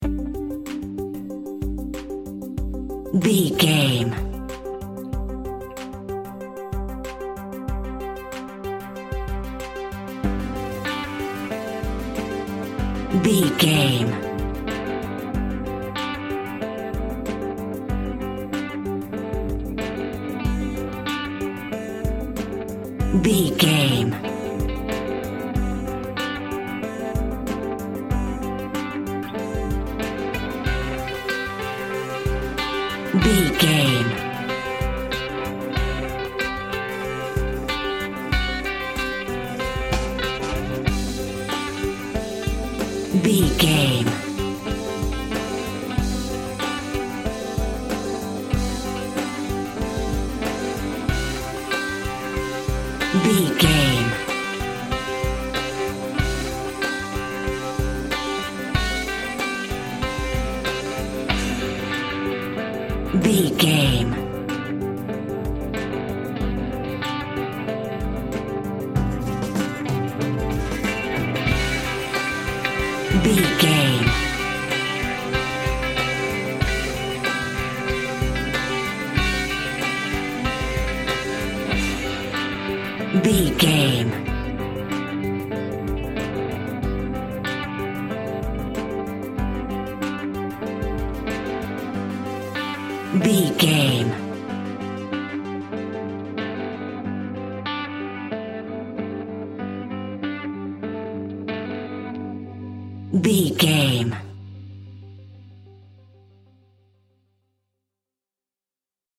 Ionian/Major
electronic
new age
techno
trance
synths
drone
glitch
Synth Pads
Synth Ambience